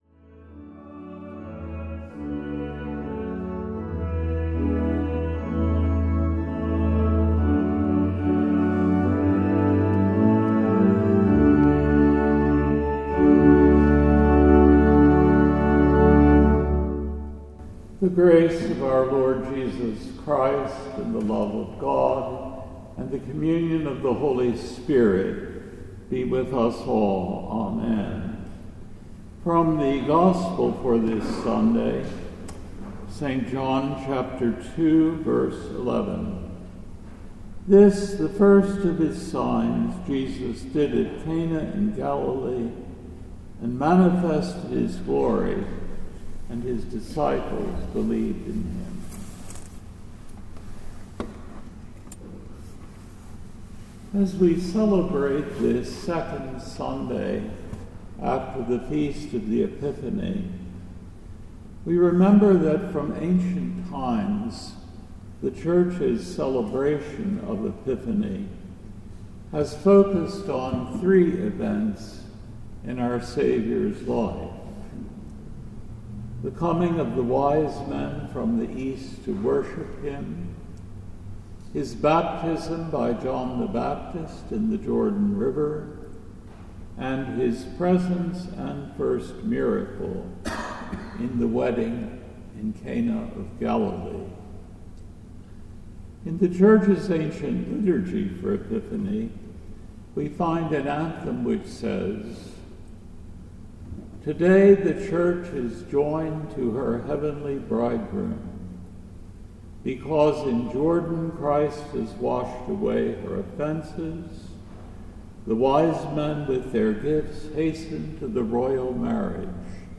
This podcast features a weekly sermon